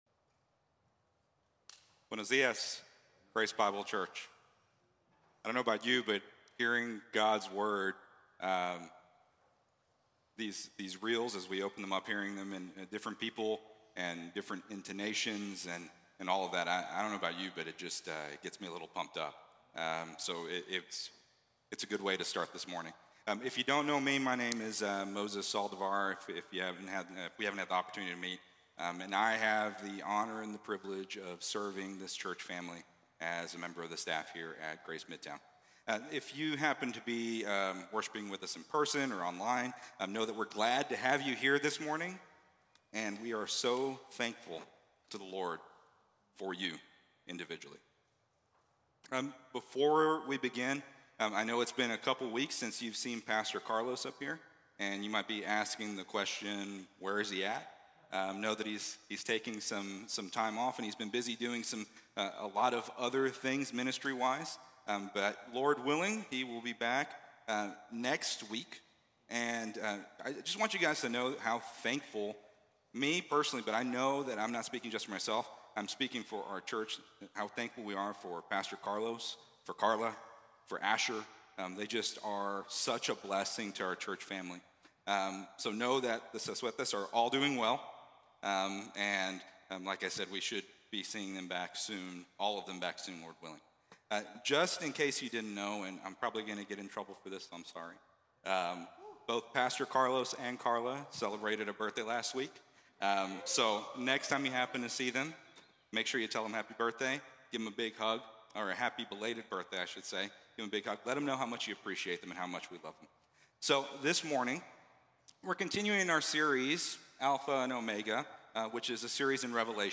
Before the Throne | Sermon | Grace Bible Church